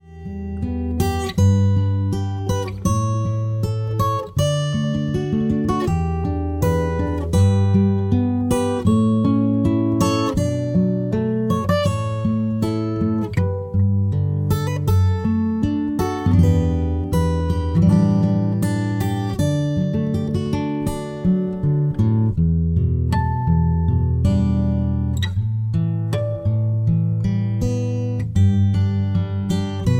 Buy Without Backing Vocals